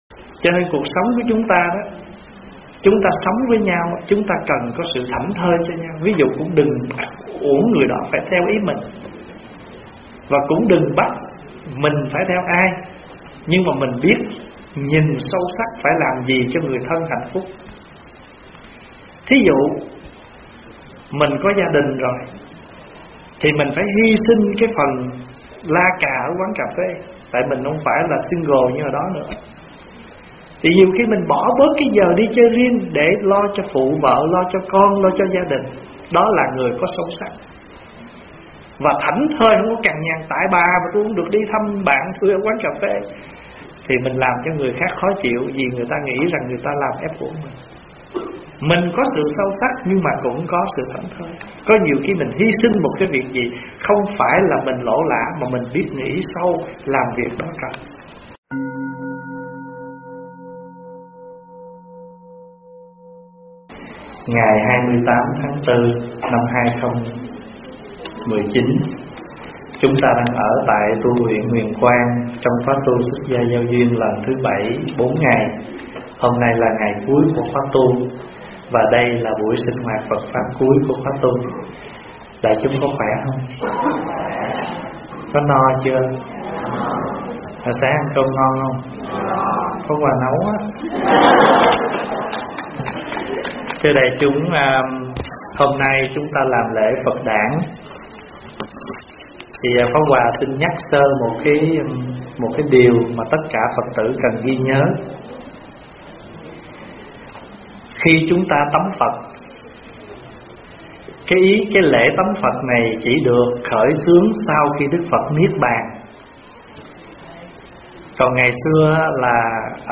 Vấn đáp Sống Sâu Sắc Thảnh Thơi
giảng tại TV Huyền Quang